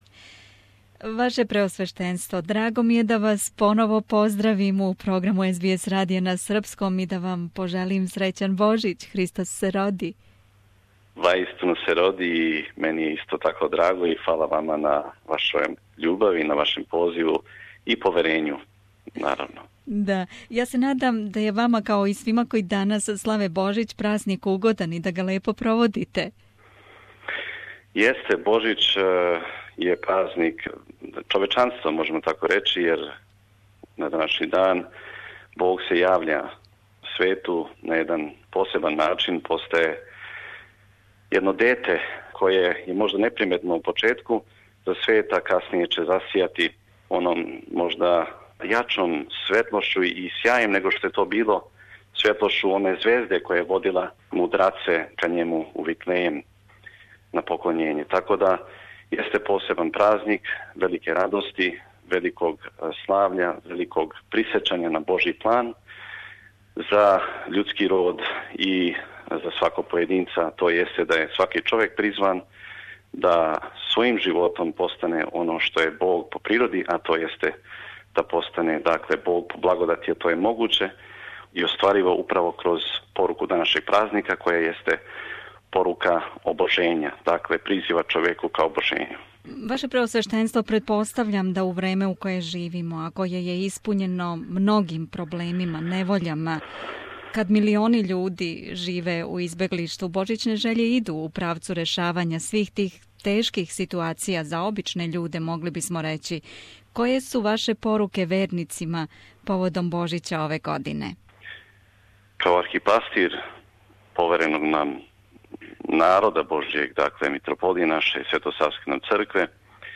Слушајте ексклузиван разговор са Његовим Преосвештенством Епископом Митрополије аустралијско-новозеландске Г. Силуаном , поводом Божића.